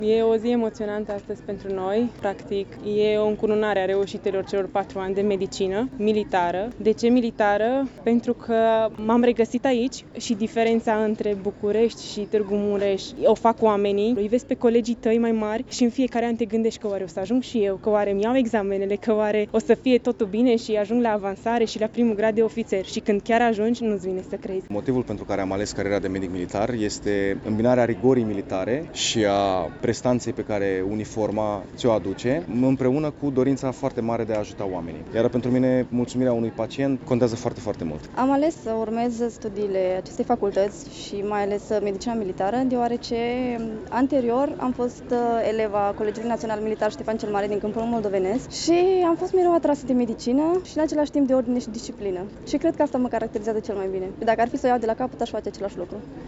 Momentul solemn a avut loc la ora 9.30, în Cetatea Medievală din Târgu Mureș, și a confirmat calitatea studenților care se pregătesc la Târgu Mureș.
Noii ofițeri au dat dovadă că educația militară poate stăpâni emoțiile: